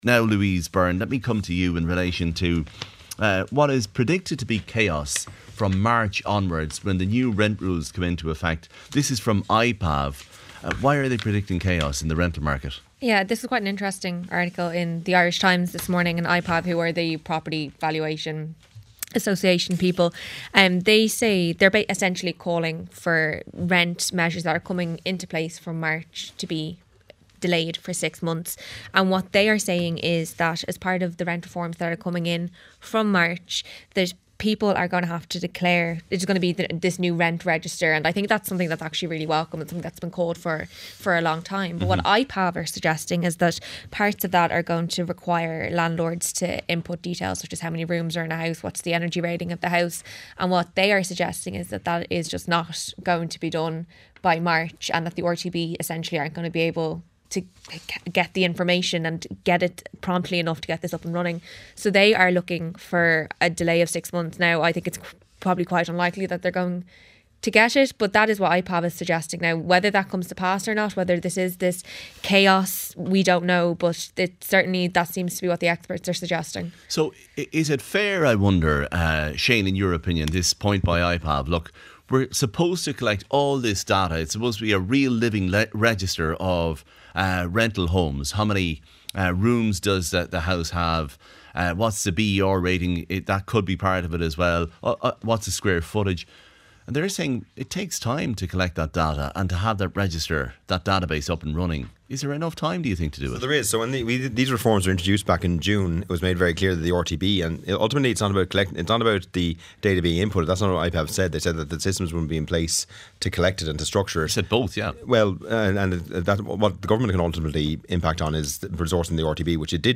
Shane Moynihan, Fianna Fáil TD for Dublin Mid-West, Ruairí Ó’Murchu, Sinn Féin TD for Louth, George Lawlor, Labour TD for Wexford